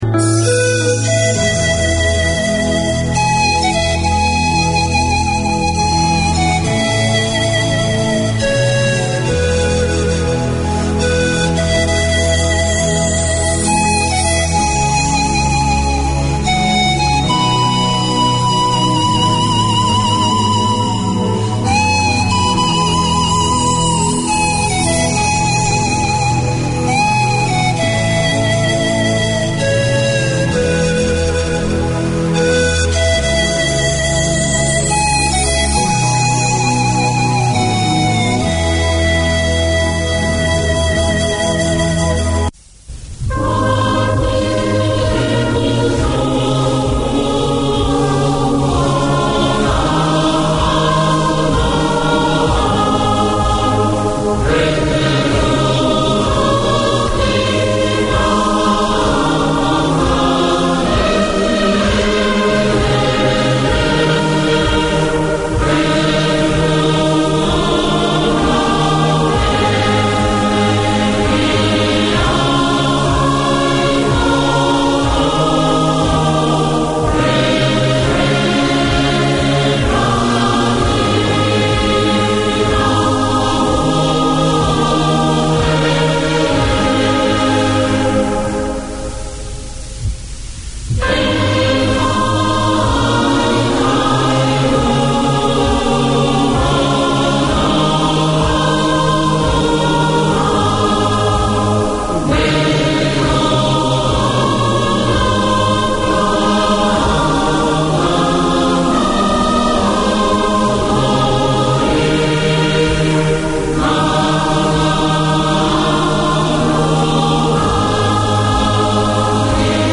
A new era in Tongan broadcasting, this is a talk show that focuses on community successes and debating issues from every angle relevant to Tongan wellbeing. Four mornings a week, the two hour programmes canvas current affairs of concern to Tongans and air in-depth interviews with Tongan figureheads, academics and successful Tongans from all walks of life.